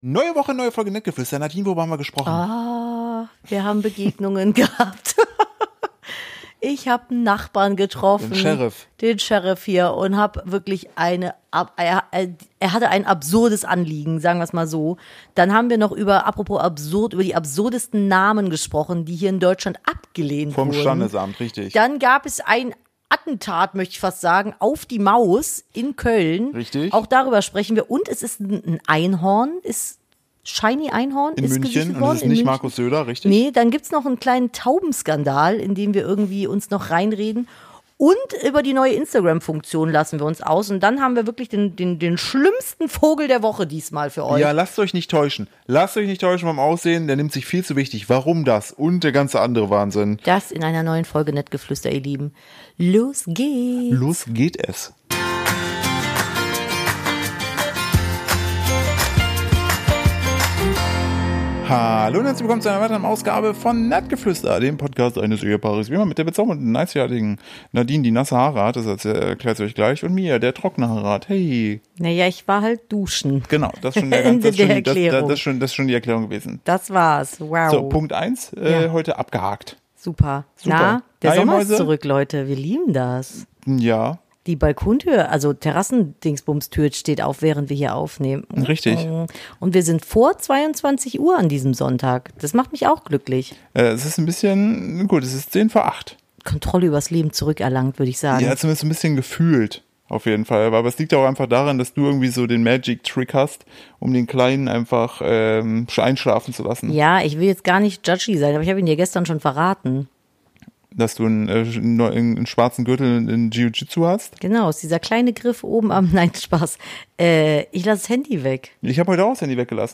Nettgeflüster - Der Podcast eines Ehepaars